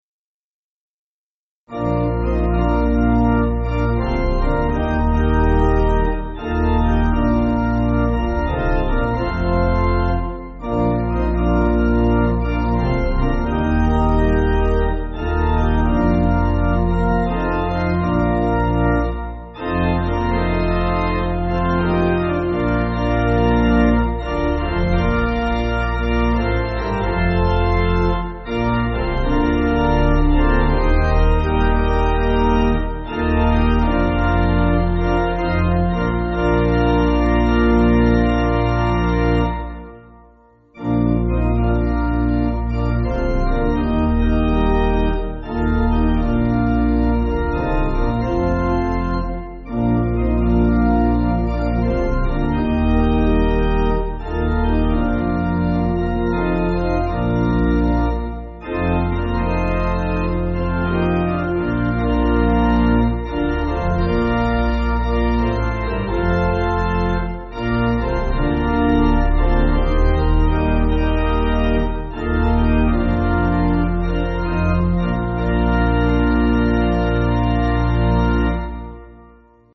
Organ
(CM)   5/Bb